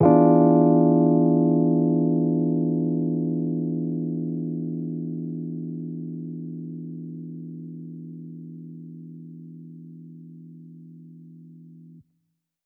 JK_ElPiano2_Chord-E7b9.wav